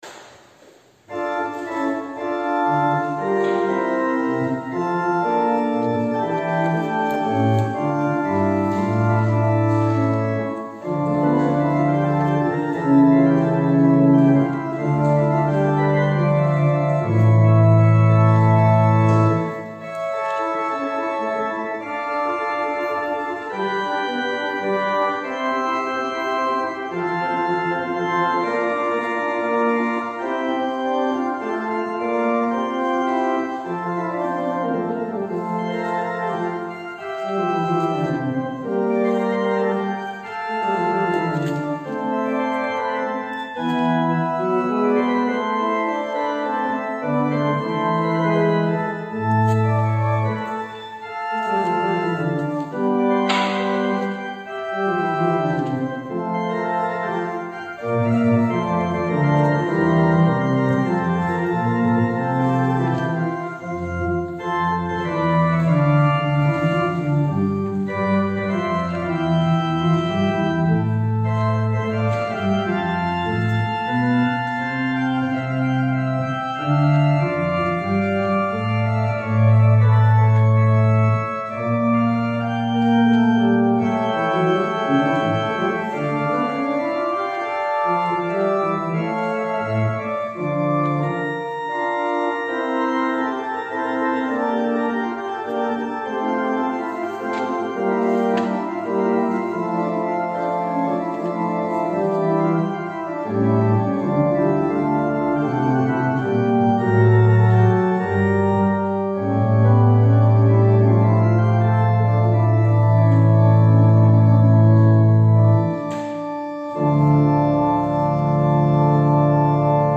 Gottesdienst aus der ref. Erlöserkirche,
Präludium